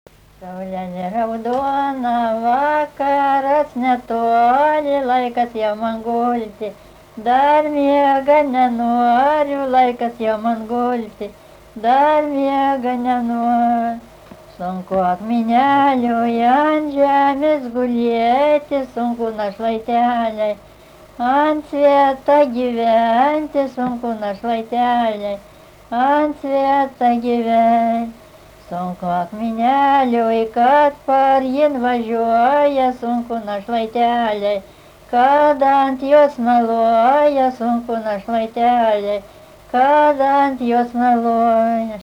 Dalykas, tema daina
Erdvinė aprėptis Viekšnaliai
Atlikimo pubūdis vokalinis